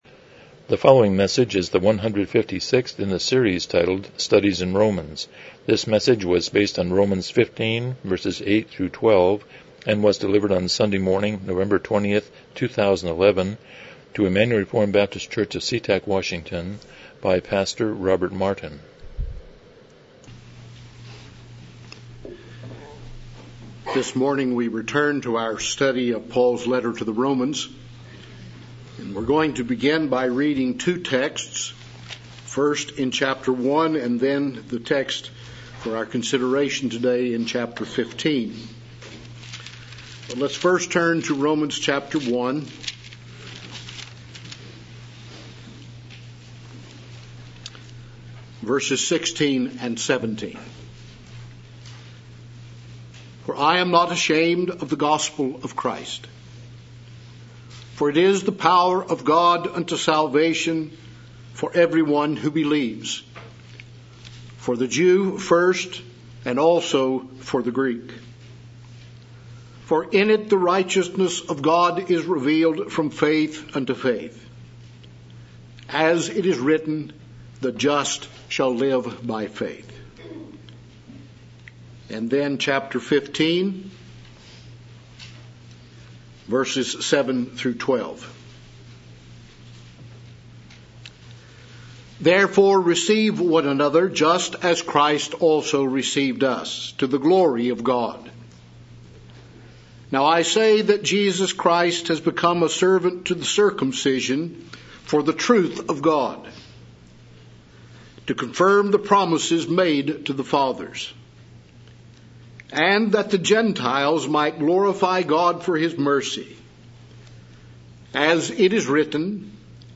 Passage: Romans 15:8-12 Service Type: Morning Worship